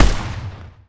AR2_Shoot 07.wav